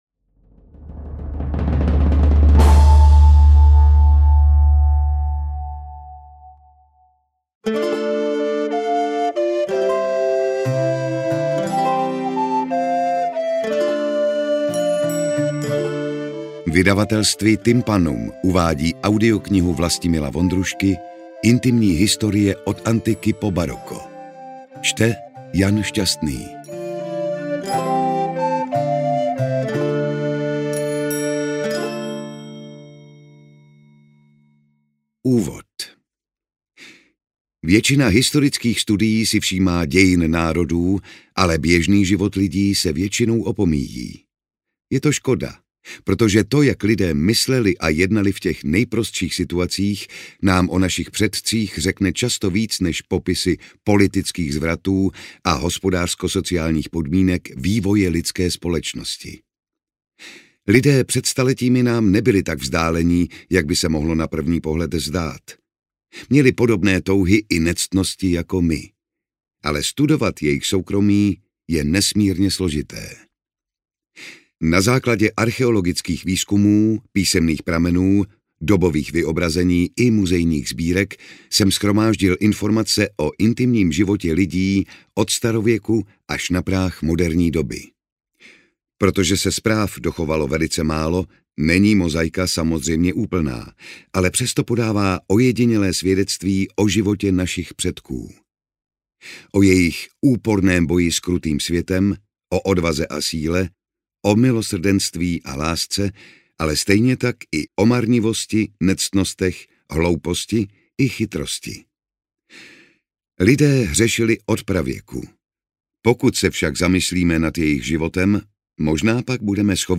Interpret:  Jan Šťastný